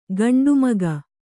♪ gaṇḍu maga